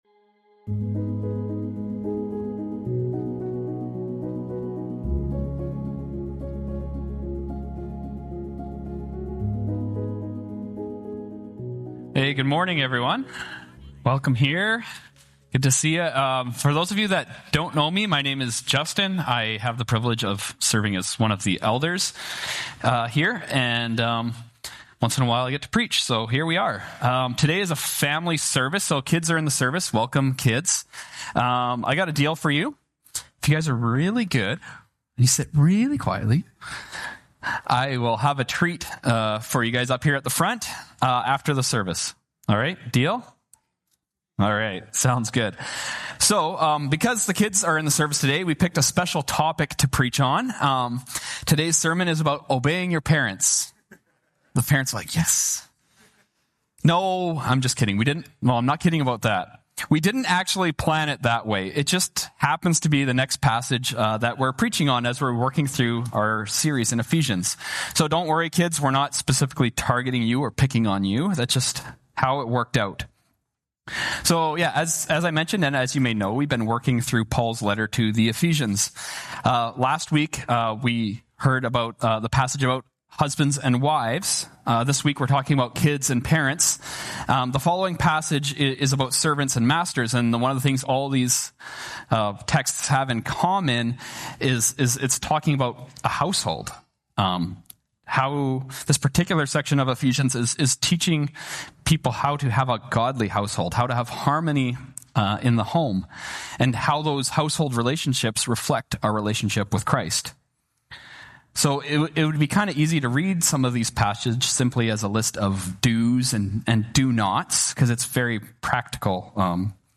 Sermon Text: Ephesians 6:1-4